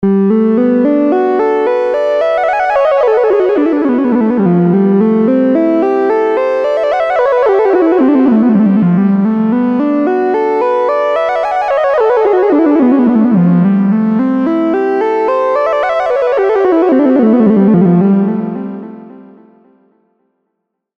Dieser semi-modulare Synthesizer wird besonders für seine eher rauen bis dreckigen Sounds und seine Flexibilität geschätzt.
Klangbeispiel Preset „Beacon Beach“